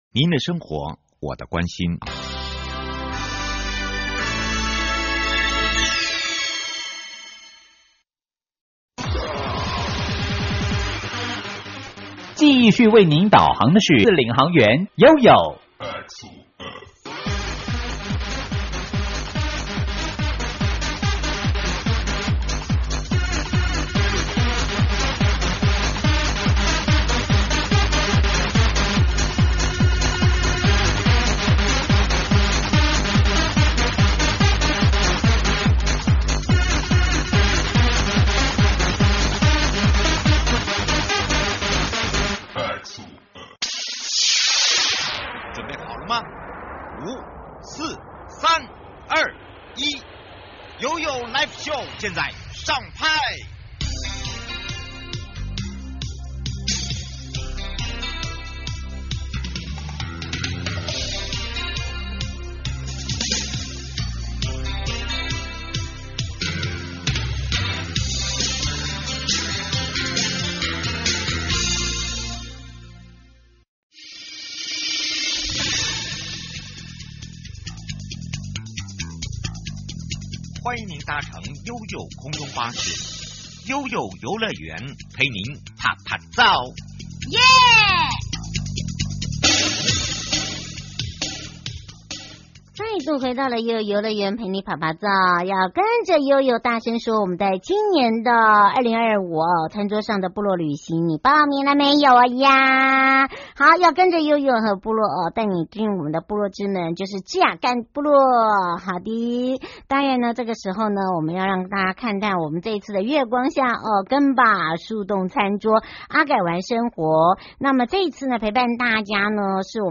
-月光下稻田腳印餐桌 11/22（六） 受訪者： 花東縱谷管理處 織羅米 86(織羅部落